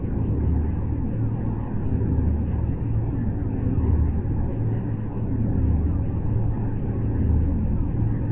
cargobay.wav